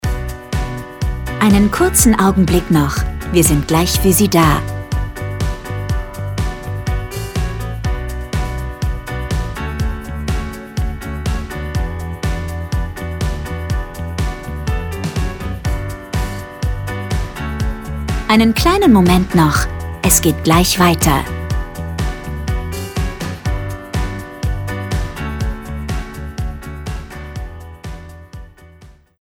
Warteschleife: